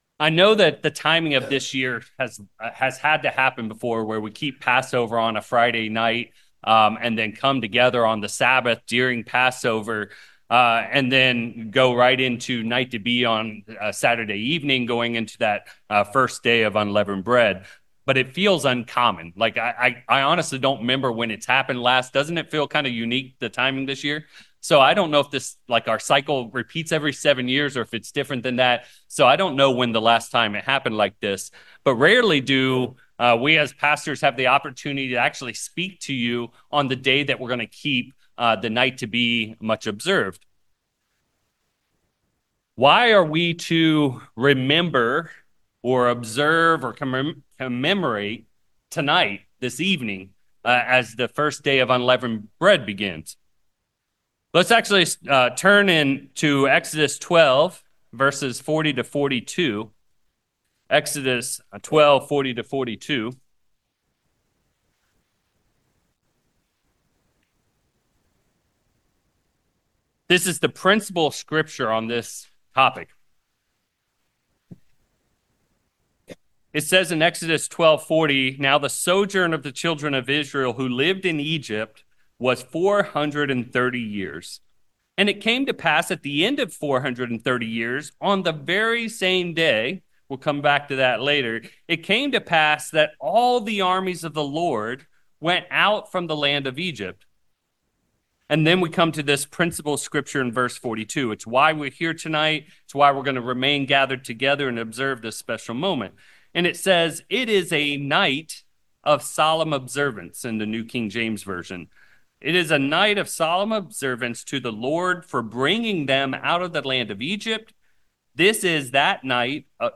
4/12/25 This is a sermon where we dive into the deep meaning of the Night to be Much Remembered/Night to be Much Observed. There is so much meaning to this night that we, as Christians, want to understand clearly as we commemorate this evening each year.